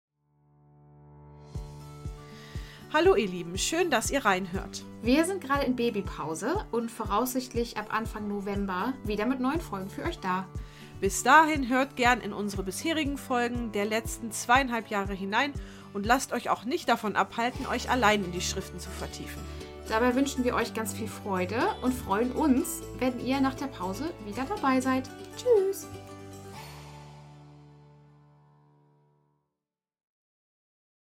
Zwei Schwestern sprechen über Christus